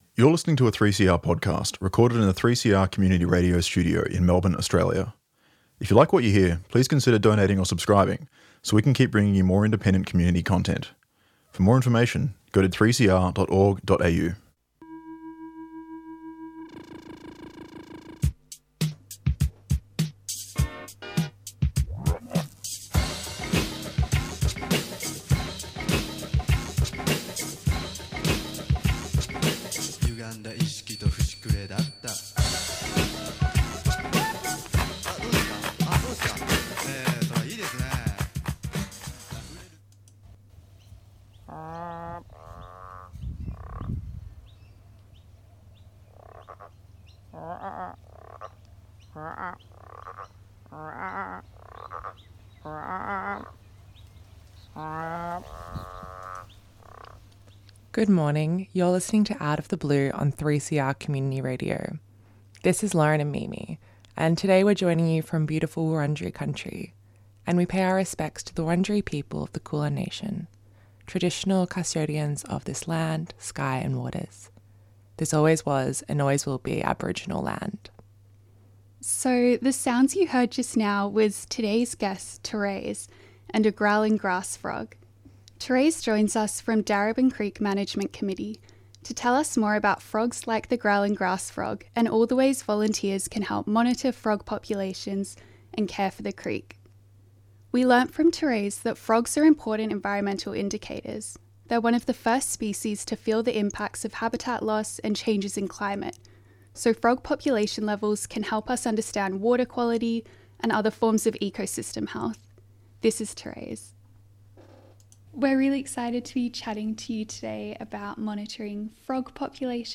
Presented by volunteer broadcasters who are passionate about marine environments, both local and across the world.